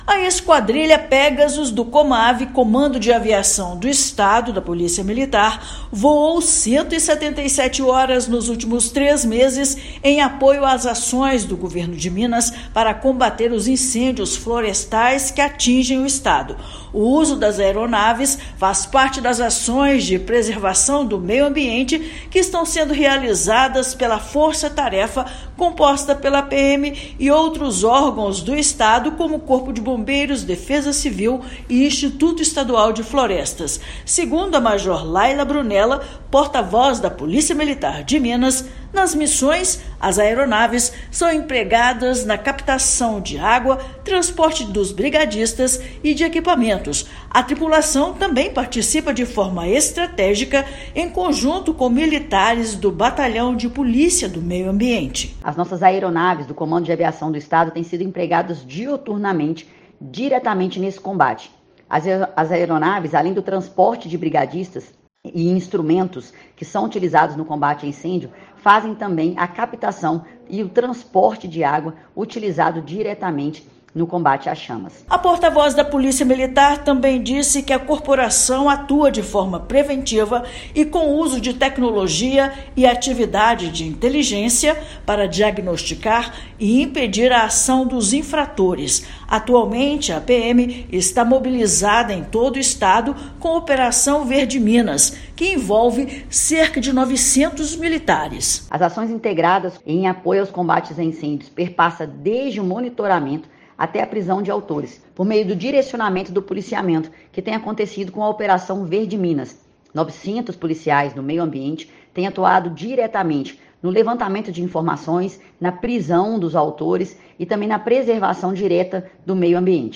Também estão sendo mobilizados cerca de 900 militares na operação Verde Minas. Ouça matéria de rádio.